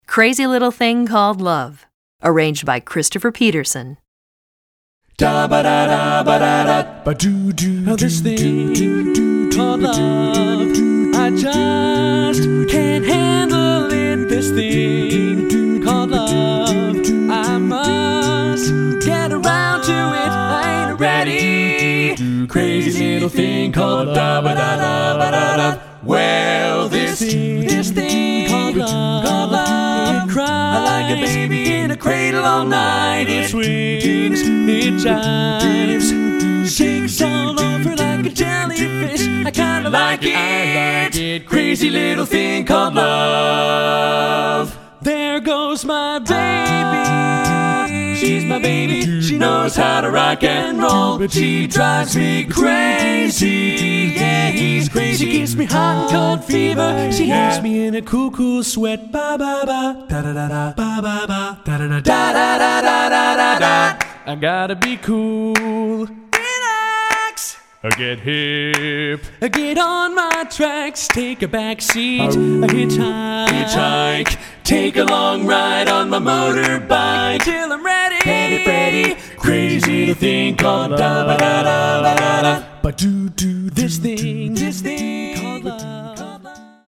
Voicing: Voice Trax